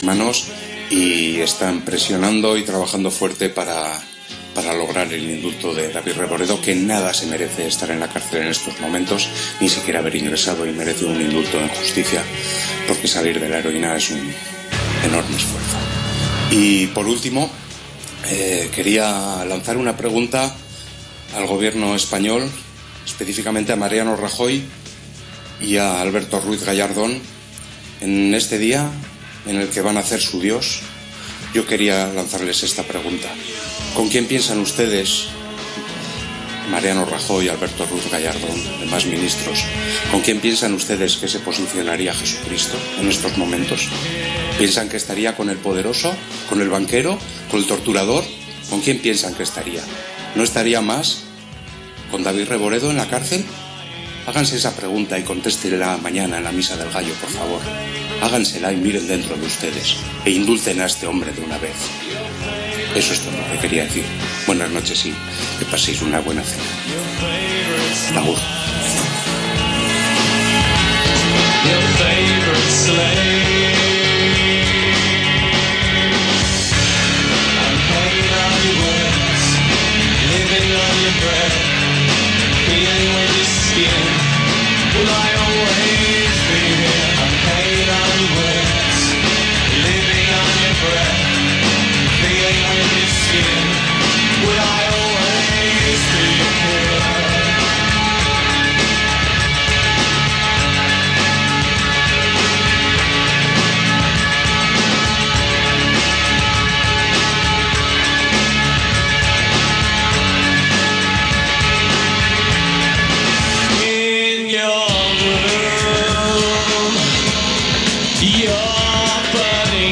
Leemos fragmentos de este libro en que se narra la lucha del personaje principal por sobrevivir en una ciudad hostil a la que ha acudido para vengar a sus amigos muertos por la avaricia empresarial.